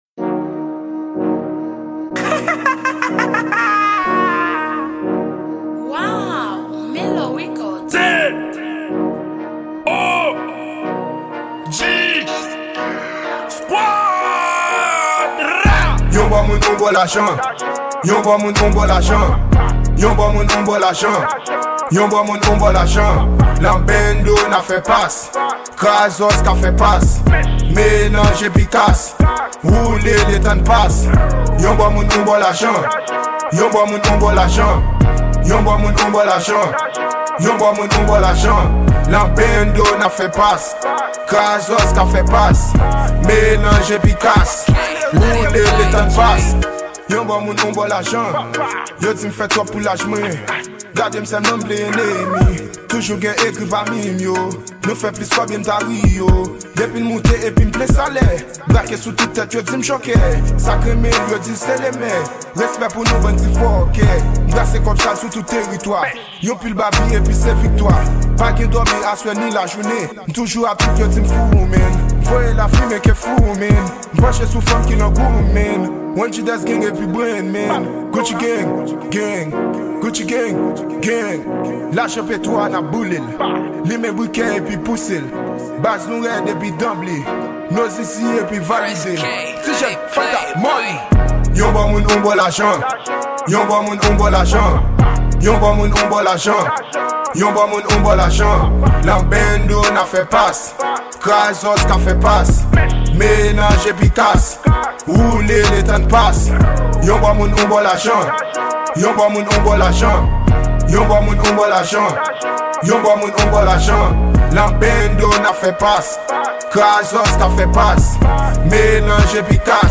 Genre: TRAP.